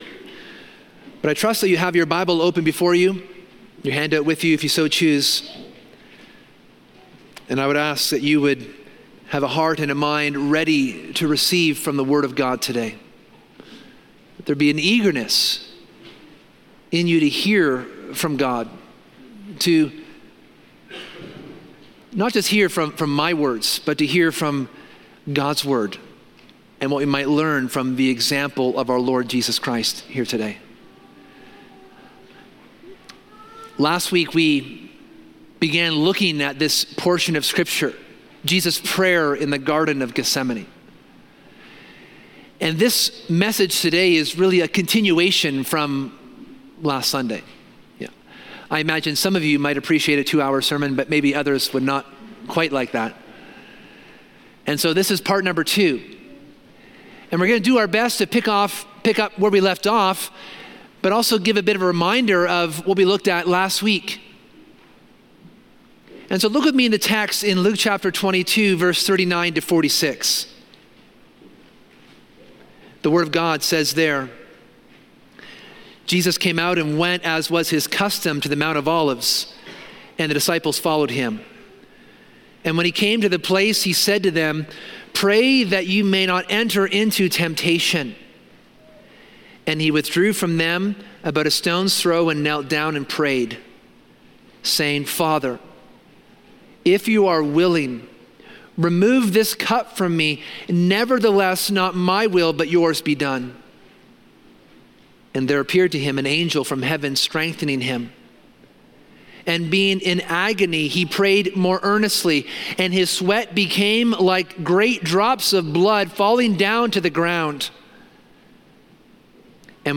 This sermon revisits Jesus’ prayer in the Garden of Gethsemane.